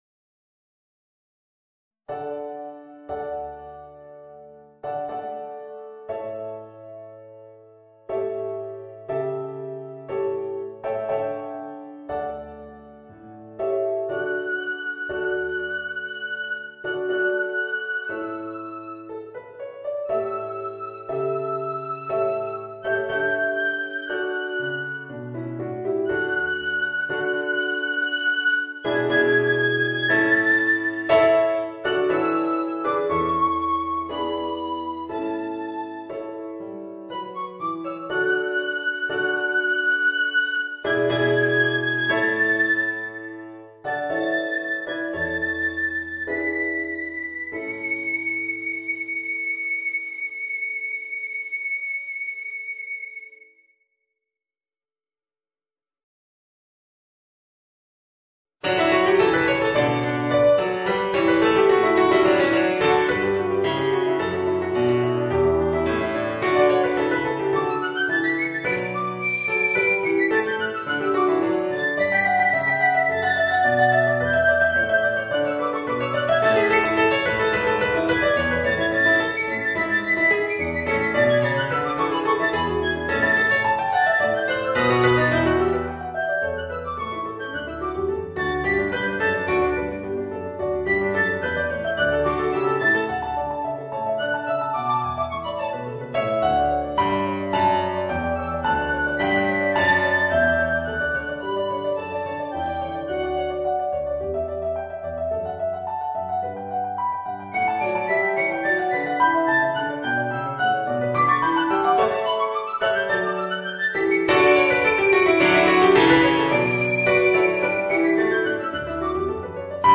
Voicing: Piccolo and Organ